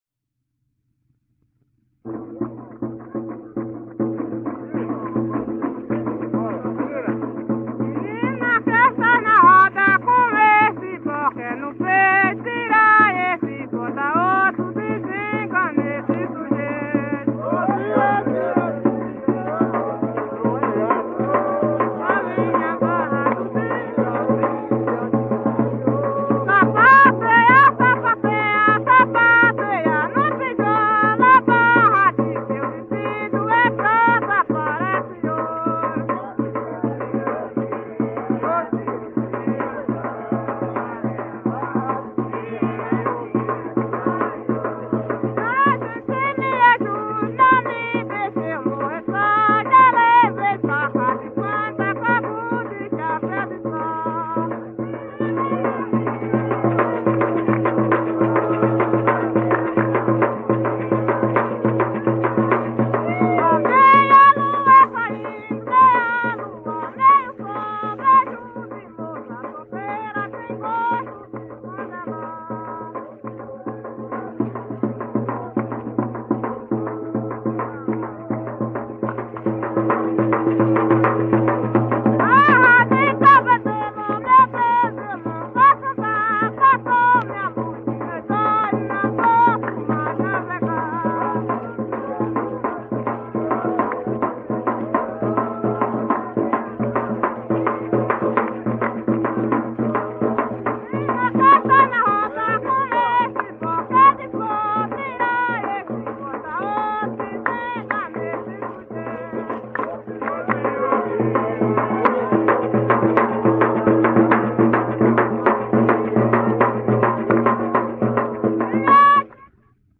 Coco – “”O dia ode odo”” - Acervos - Centro Cultural São Paulo